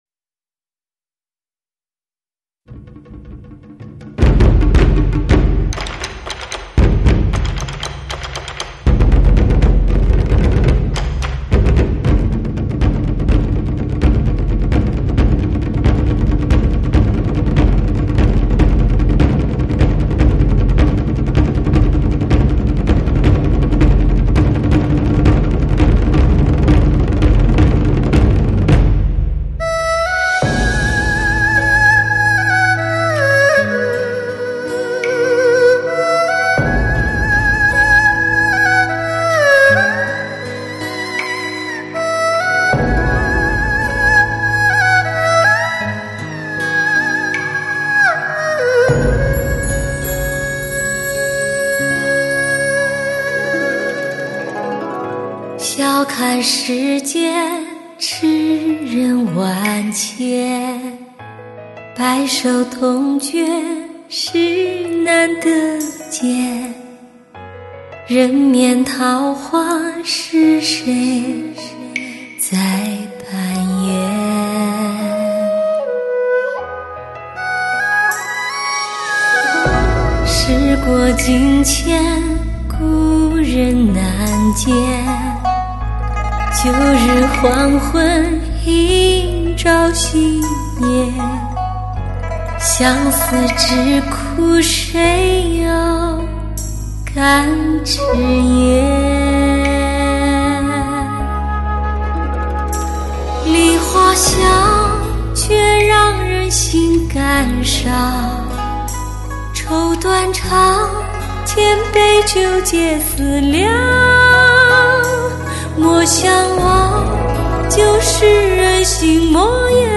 有的48TIT/192KHZ极限音质采样技术多彩丰富的配乐手法，最让人倾心的音乐元素使整张唱片